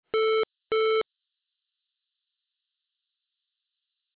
beepbeep4s.mp3